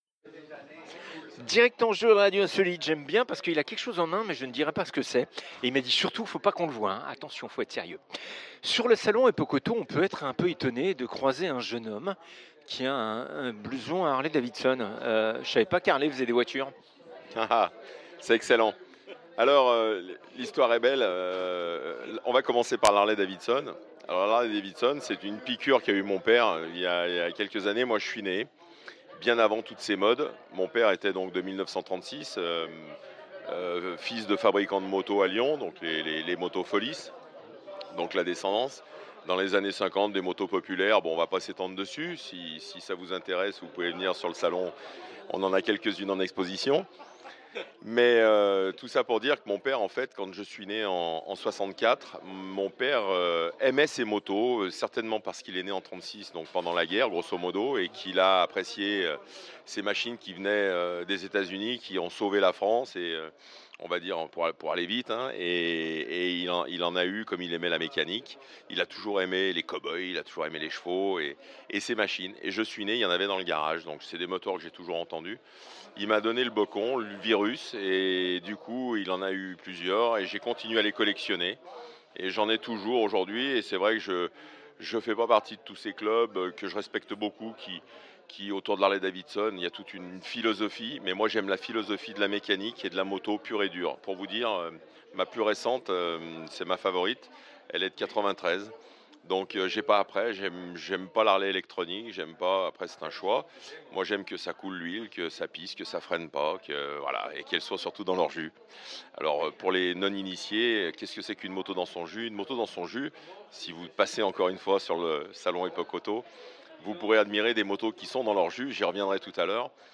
Harley Davidson, plus qu'une marque de moto, un style de vie - en direct d'Epoqu"Auto Lyon 21